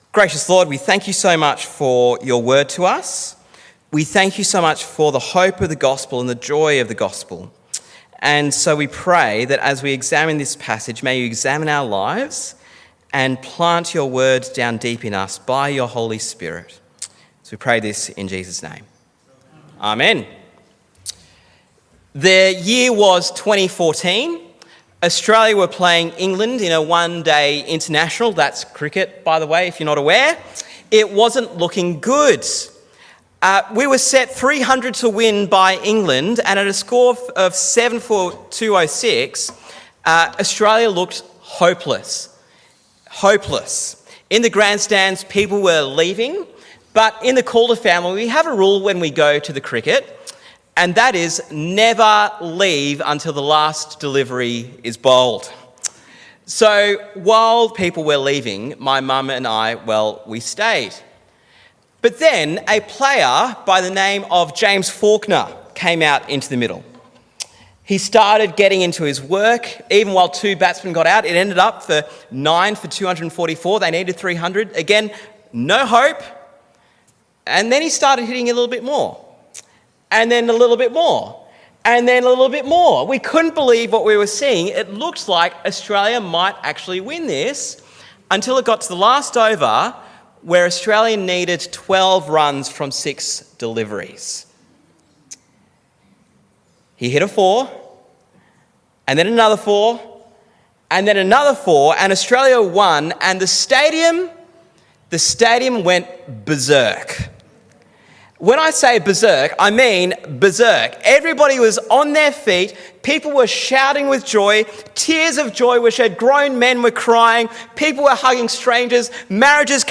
Sermon on Psalm 126